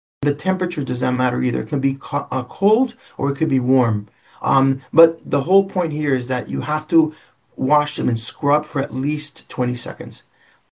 an urgent care physician